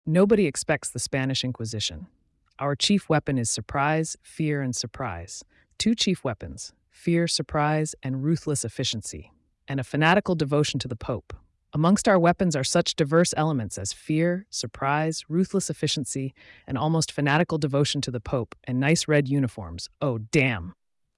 This audio file was generated by ChatGPT when ask to write and recite a poem about Python data structures
python_poem.mp3